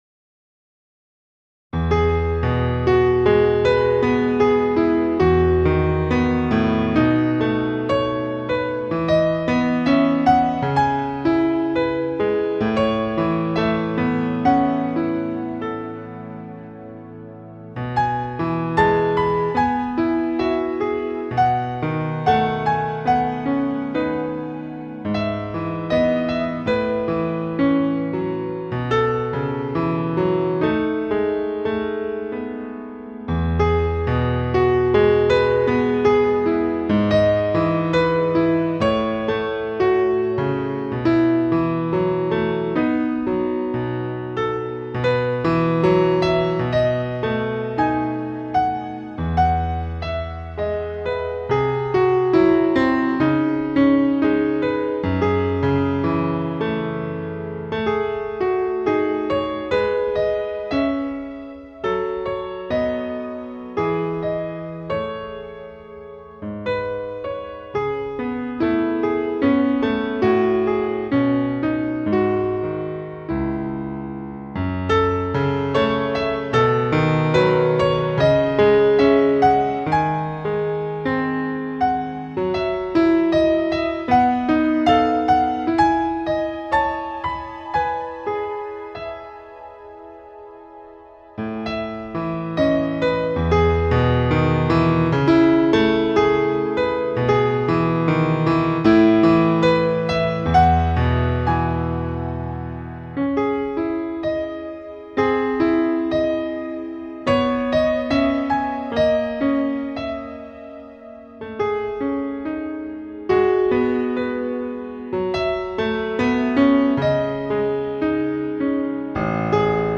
* piano transcription and performance by